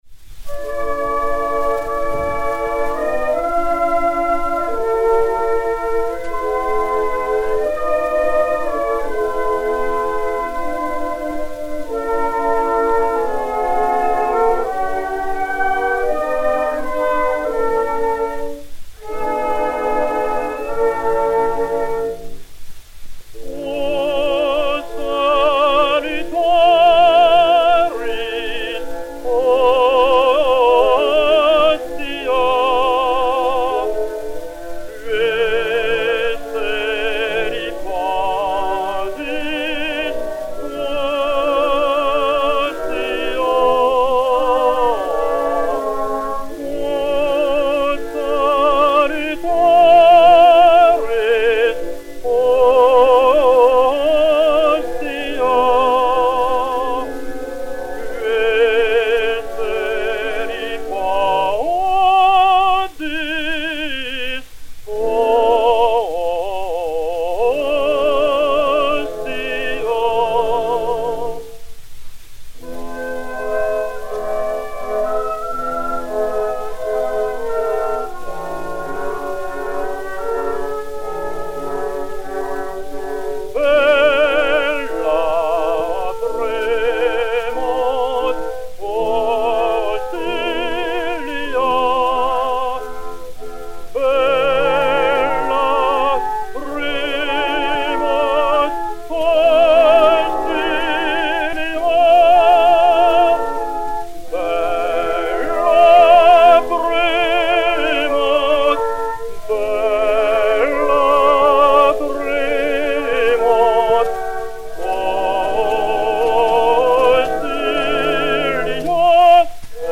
Orchestre
Disque Pour Gramophone 032193, mat. 01805v, enr. le 02 janvier 1911